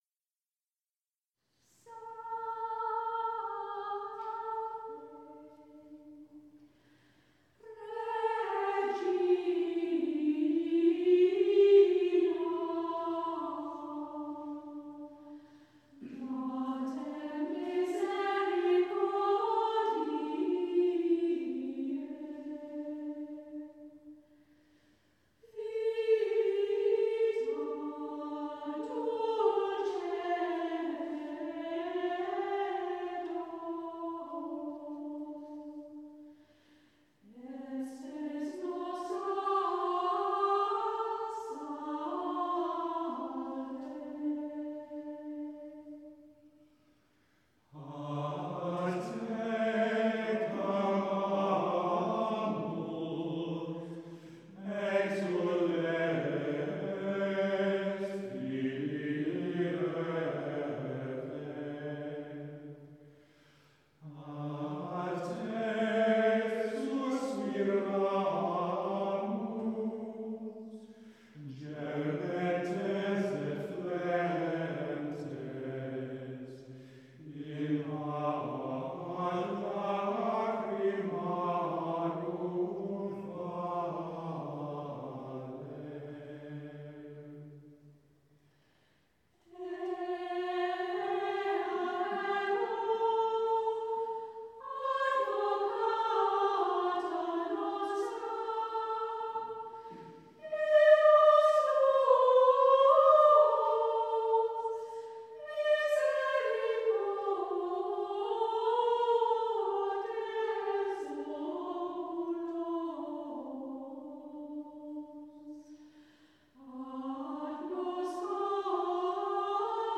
The Choir of Boxgrove Priory
with The Boxgrove Consort of Viols
Recorded live in Boxgrove Priory on the evening of 25th June 2013
Salve Regina - plainsong